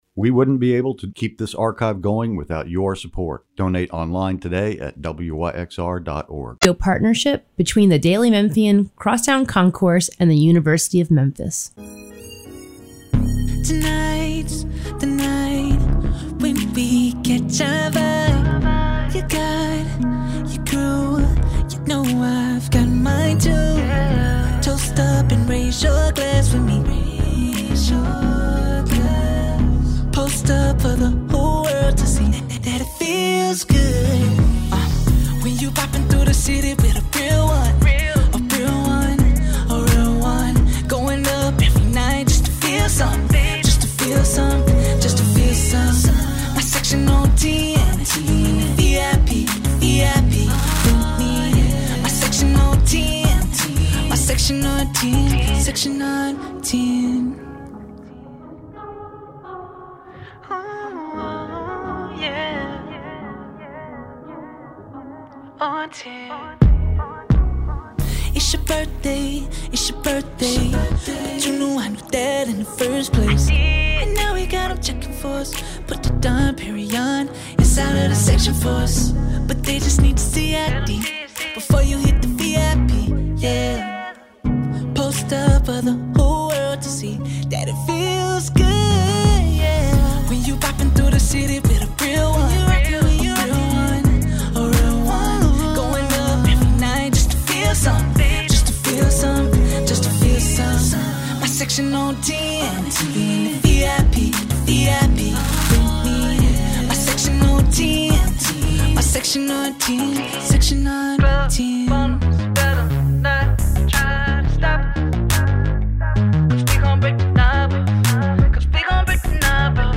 K-Pop idols